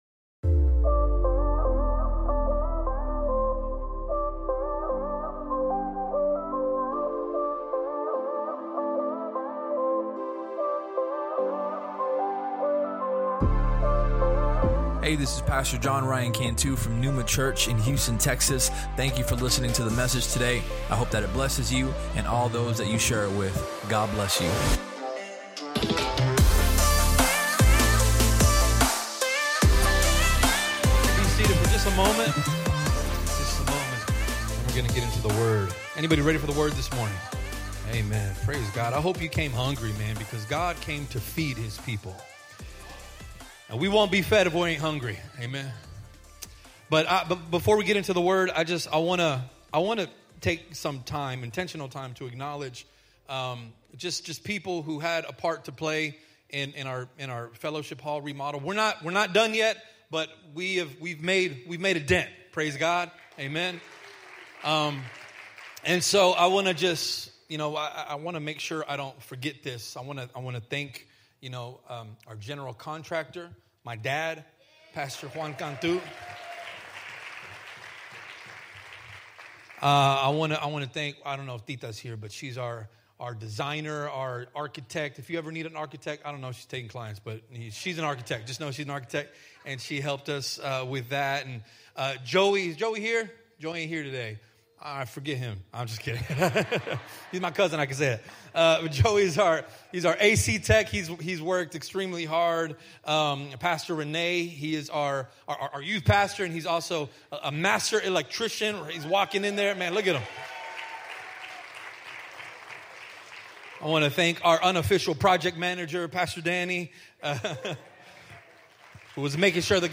Sermon Topics: Faith, Peace If you enjoyed the podcast, please subscribe and share it with your friends on social media.